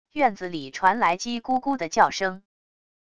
院子里传来鸡咕咕的叫声wav音频